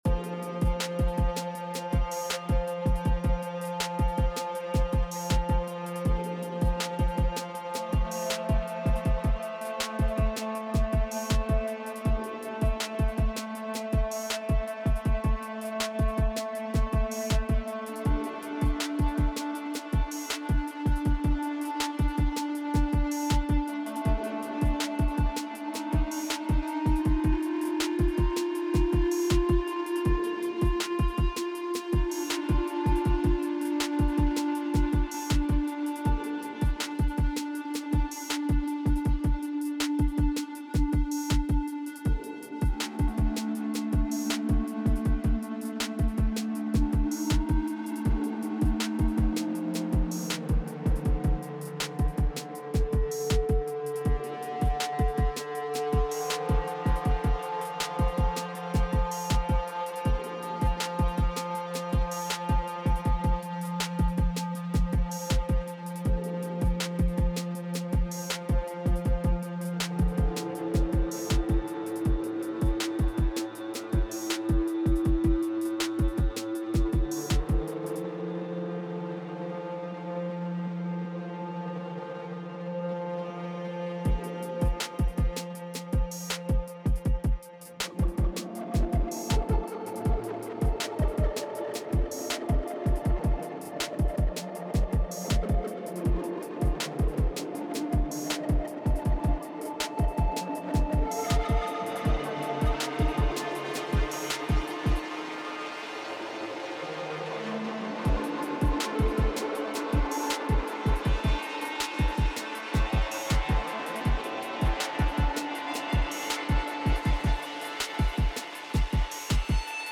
A completely random/improvised jam. As you can tell by the music (I hope), I am feeling down…
Softpop 2 is the synth. All effects are from Ksoloti Gills. Sorry for the noisy or annoying parts. I am just tweaking knobs…